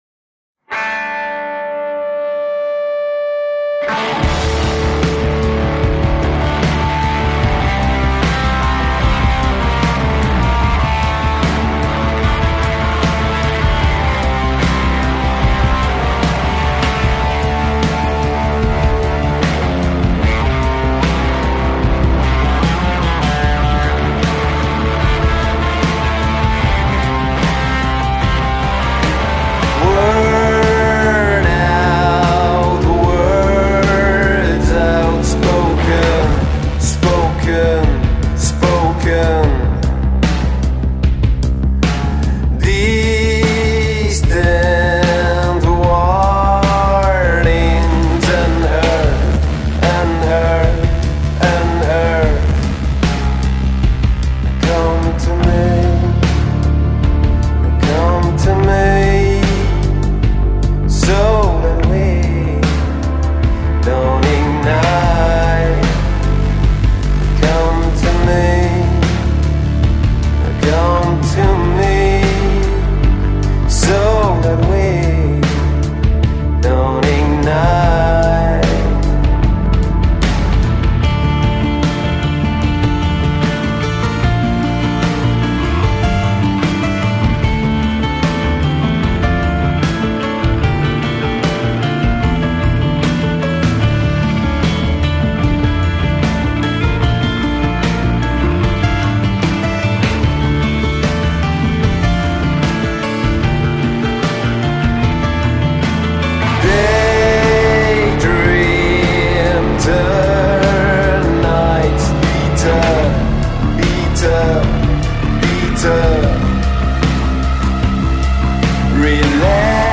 Parisian post-rock group